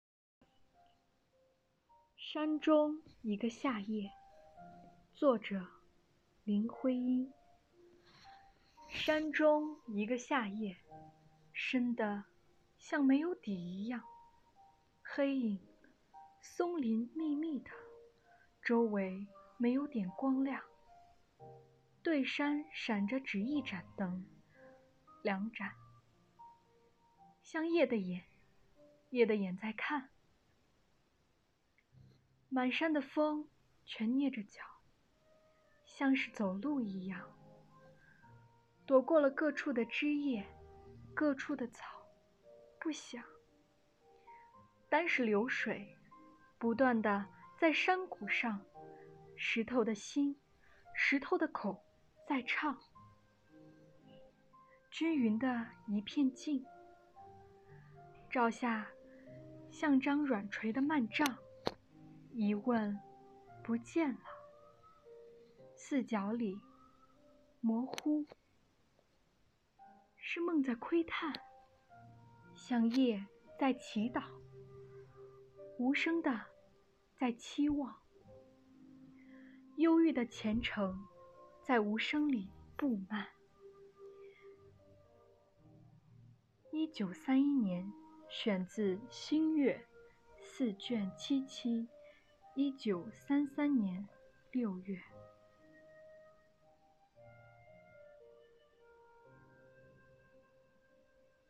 “悦读·新知·致敬”主题朗读比赛|优秀奖